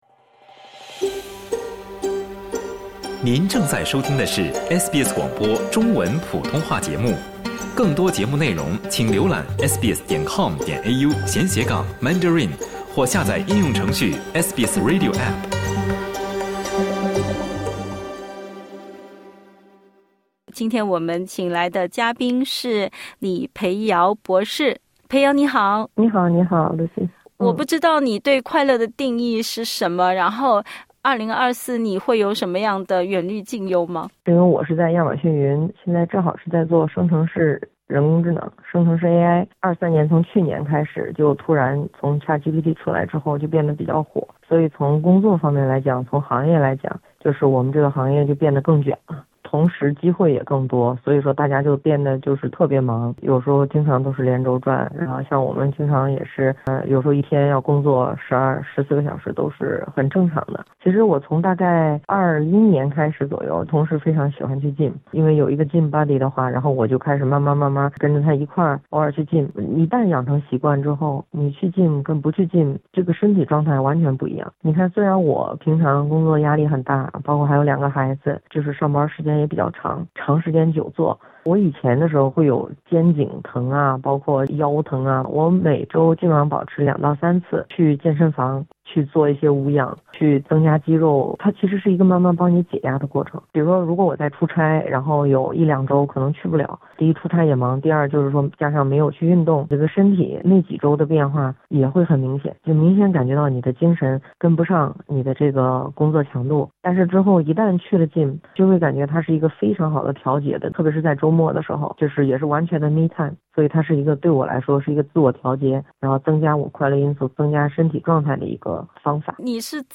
（点击音频收听详细采访）